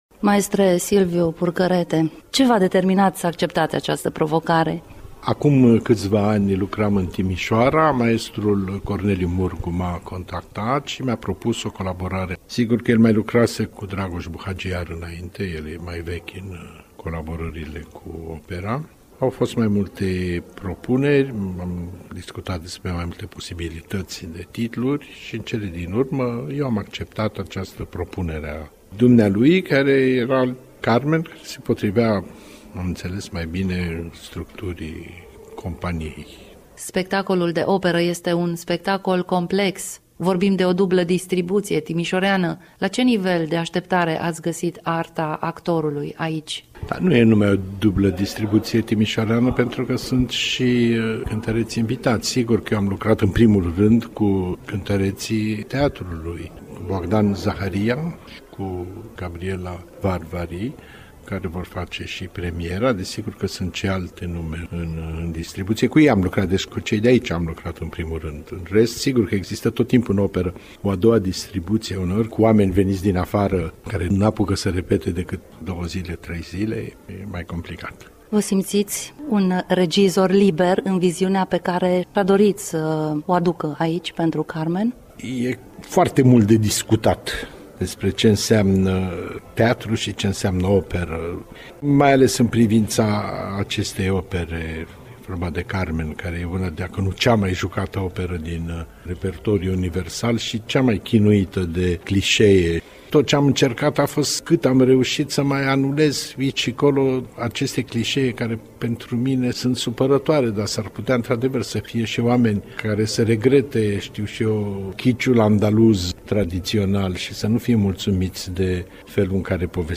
AUDIO / Silviu Purcărete, regizor